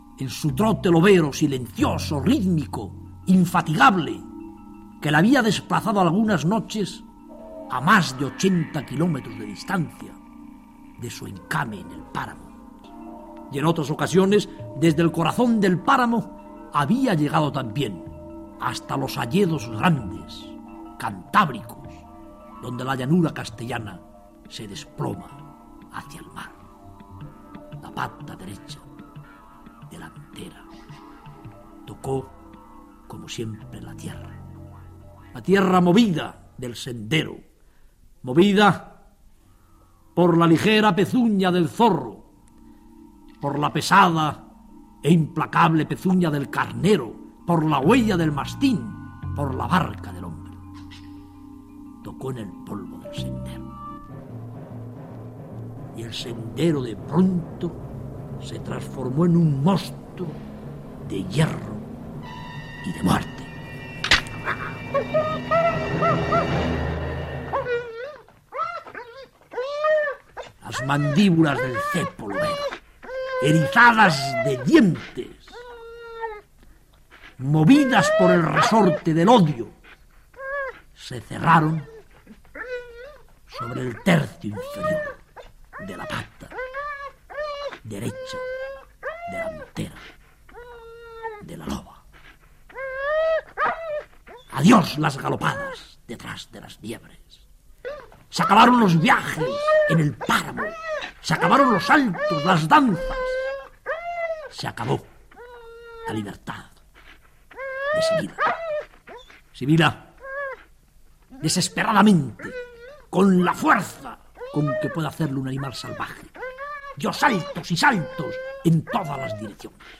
En 1976, Félix R. de la Fuente narró el escalofriante relato del sufrimiento de un lobo cuando es mutilado al caer en un cepo.